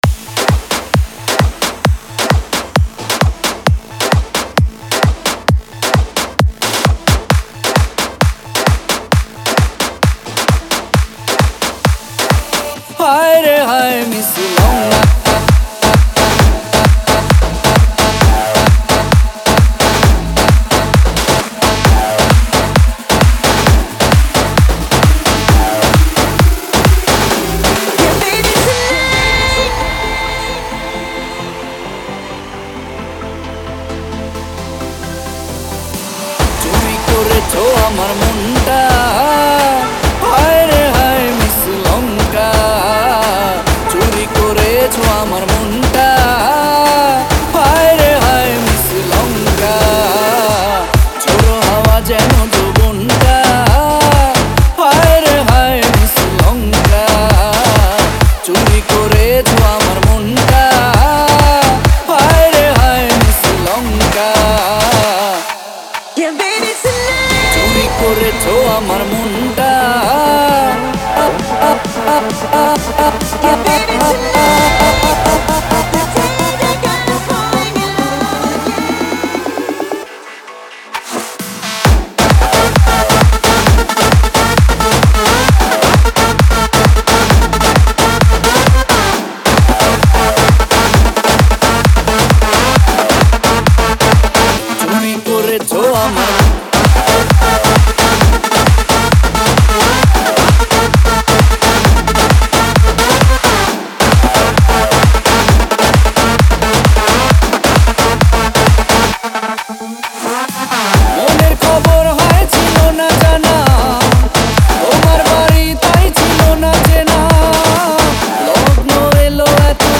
Tollywood Single Remixes